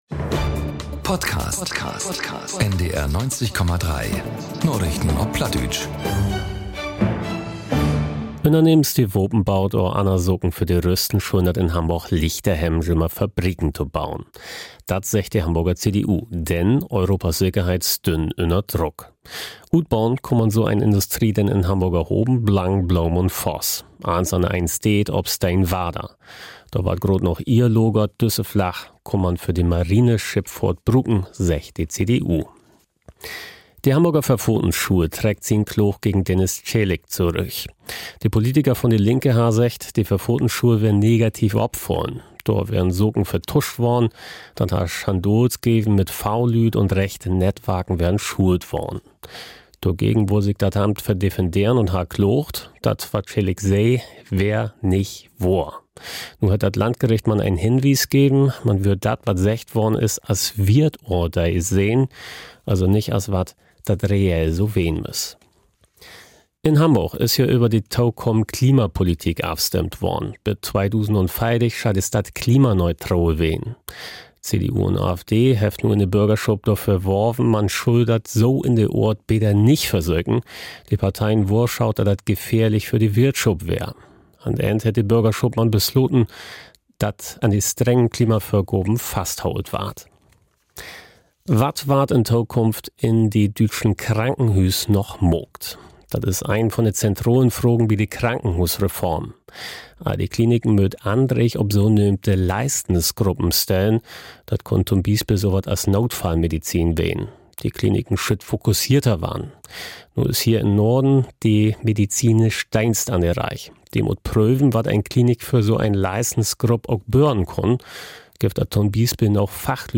Narichten op Platt 11.12.2025 ~ Narichten op Platt - Plattdeutsche Nachrichten Podcast